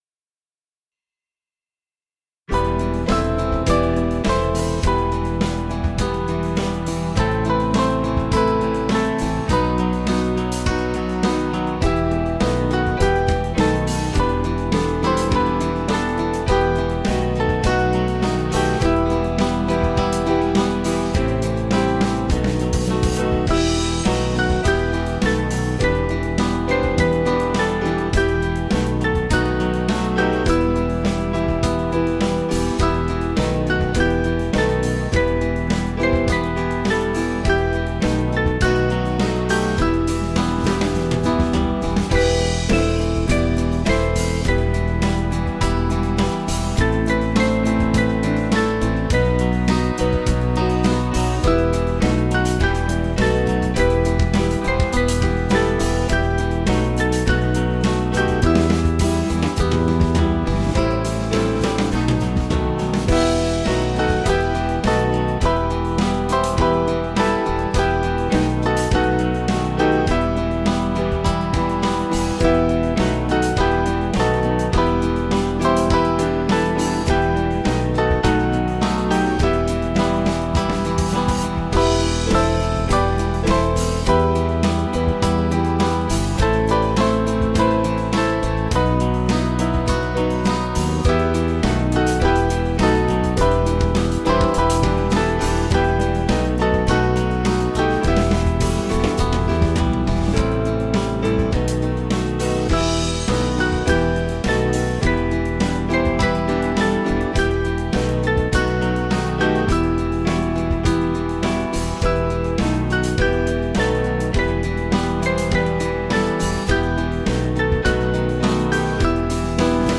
Small Band
(CM)   4/Em 482.1kb